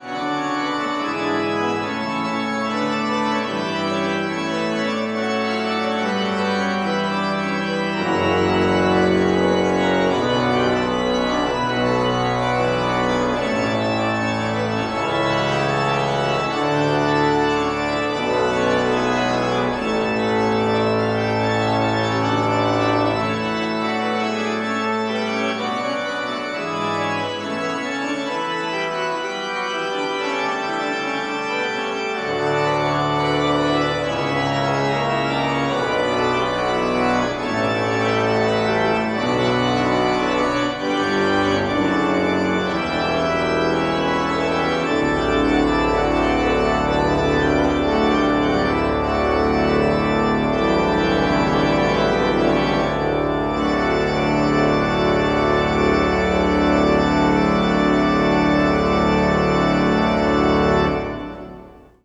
Tetrahedral Ambisonic Microphone
Organ Recital
Recorded December 12, 2009, in the Bates Recital Hall at the Butler School of Music of the University of Texas at Austin.